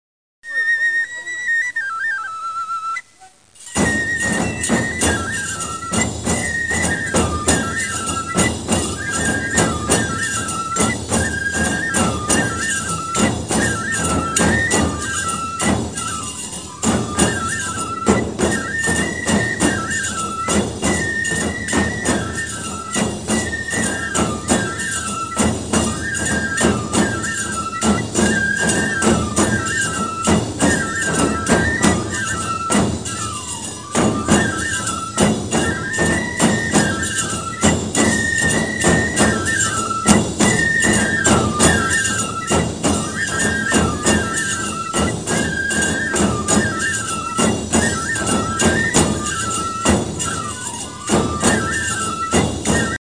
このページ「備忘録」には写真プリント、カセットテープに録音された囃子部の演奏など、捨てがたい記録を電子データ化し保存してありますのでご視聴頂ければ幸いです。
《 愛好会囃子部の演奏 》
進行ばやし　戻りばやし